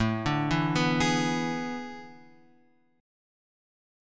A Major 7th Suspended 2nd Suspended 4th